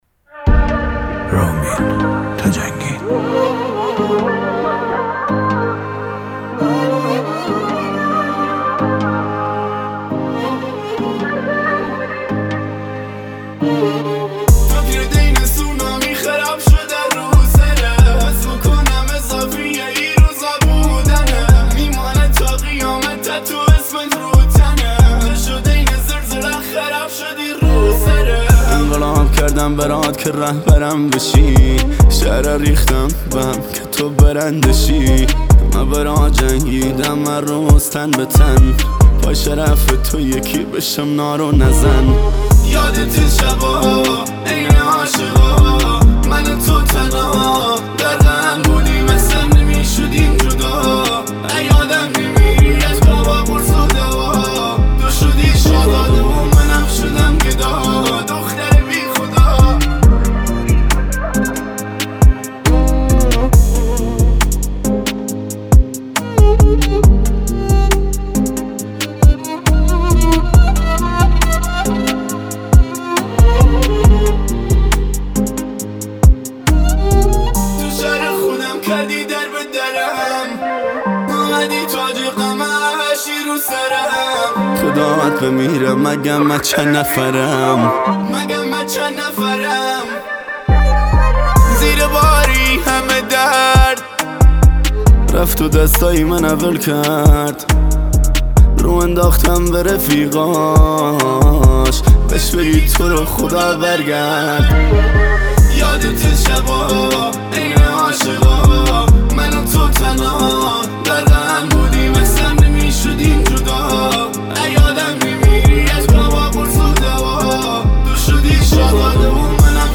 آهنگ کردی غمگین آهنگ های پرطرفدار کردی